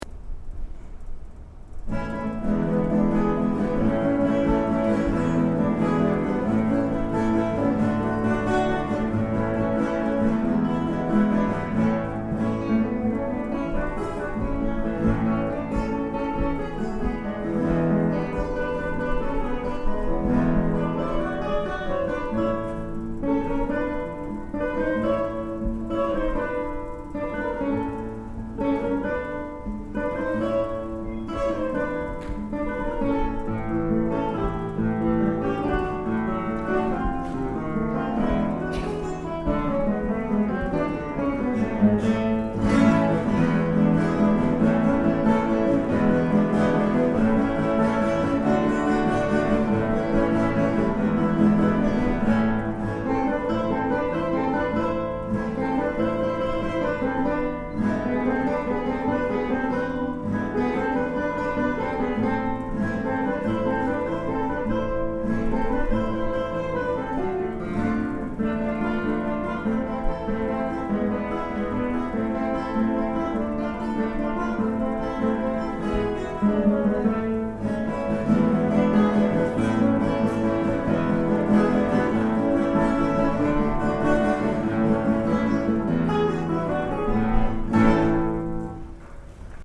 ギターコンサート
ensemble
演奏：コンセール ２１